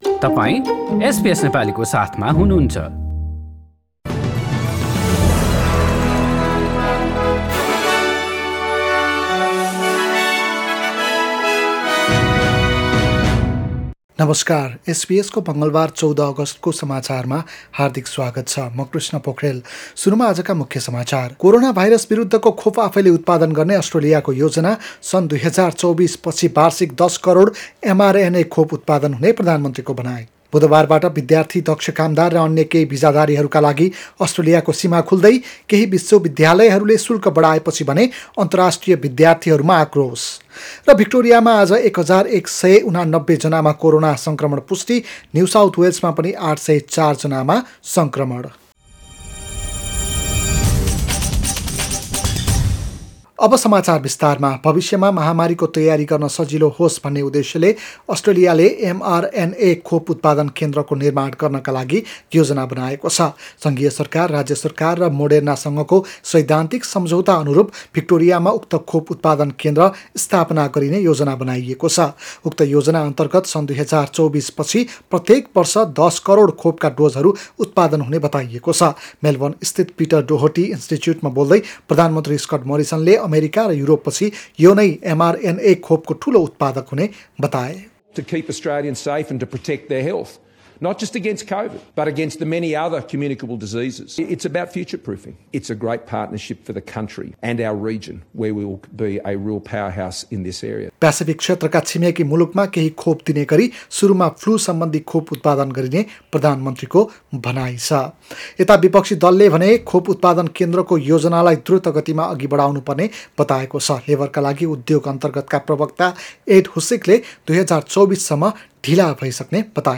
Listen to the latest news headlines from Australia in Nepali